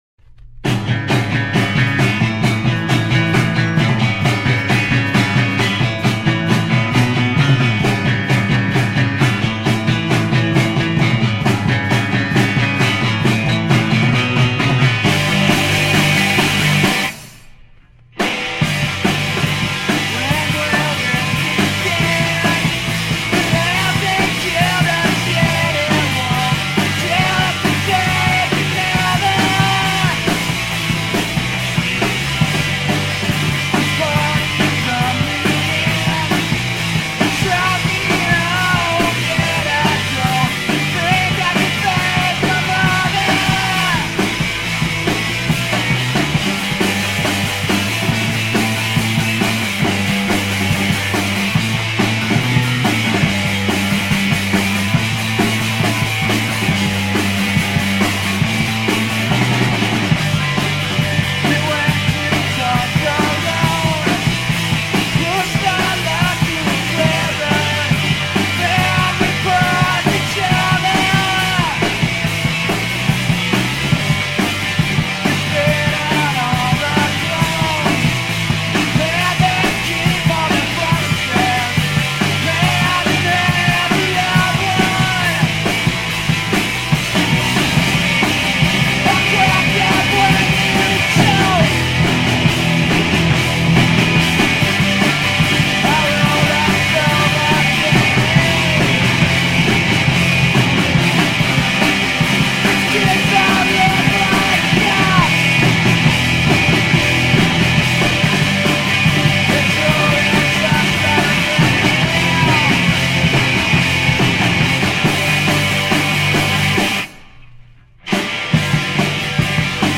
guitar, vocals
bass
drums